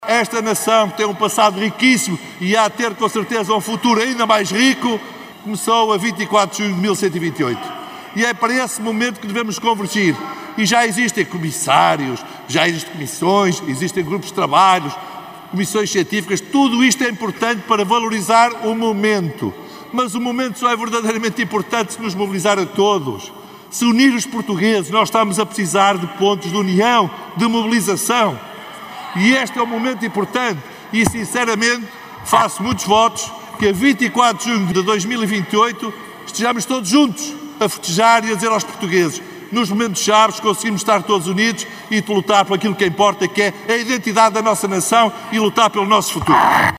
No mesmo debate, na Assembleia da República, o também vimaranense, deputado do PSD, Emídio Guerreiro, destacou a importância de mobilizar todo o país.